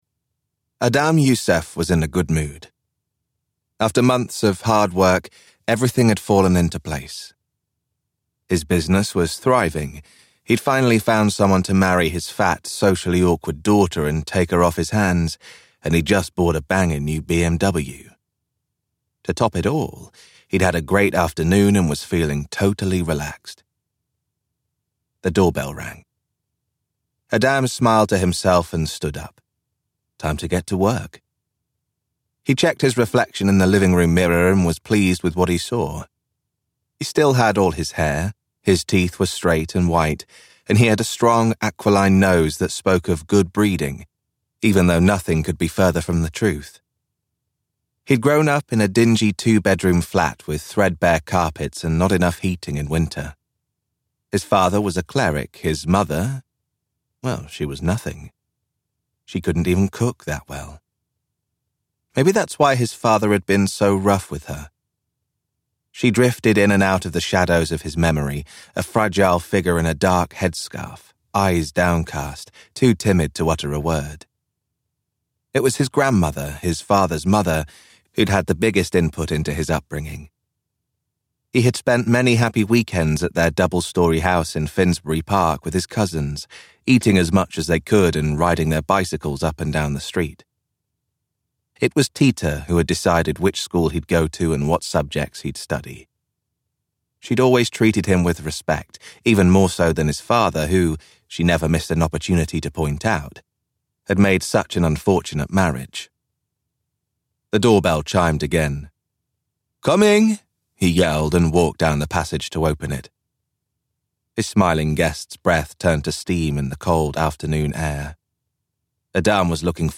The West London Murders - Vibrance Press Audiobooks - Vibrance Press Audiobooks